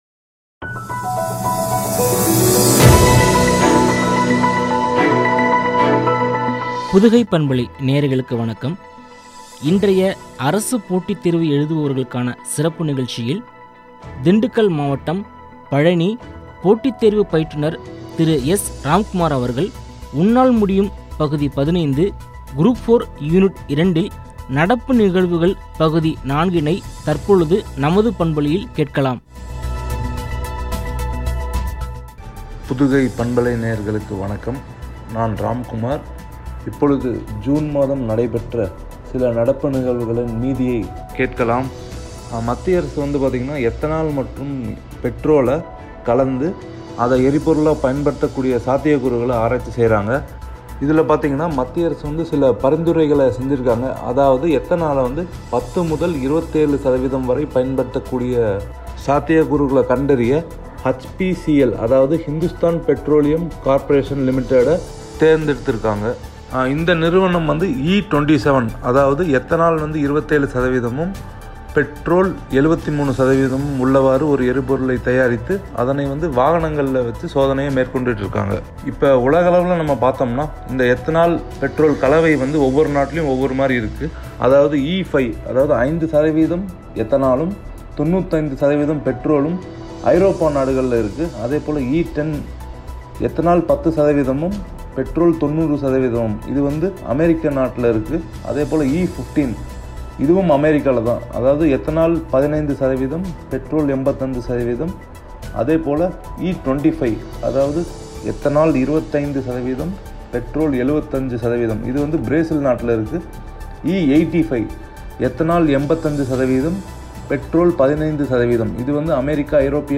(குரூப் 4 – Unit 02 – நடப்பு நிகழ்வுகள் – பகுதி 04 ), குறித்து வழங்கிய உரையாடல்.